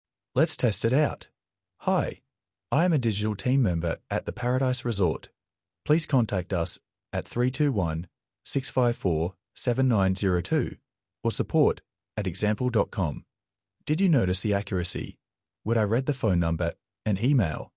Premium Voices suitable for real-time streaming.
Australian
masculine, caring, warm, empathetic